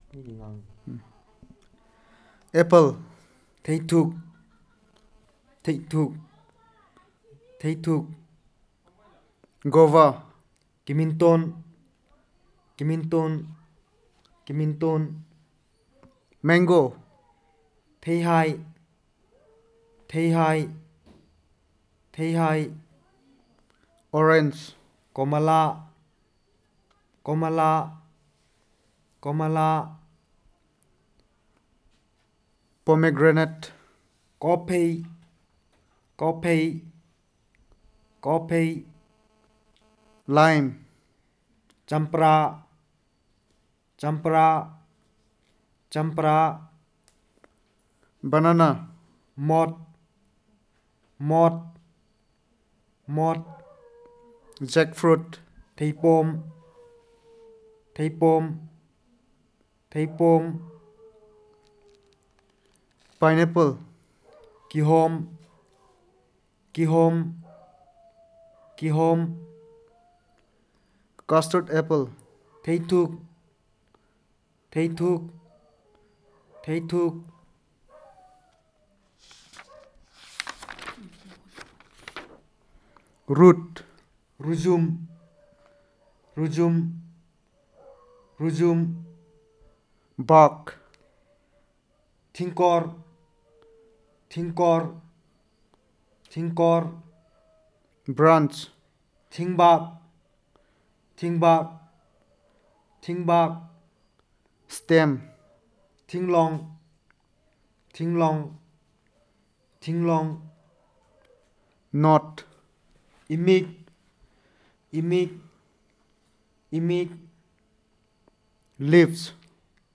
Elicitation of words about fruits, parts of tree and time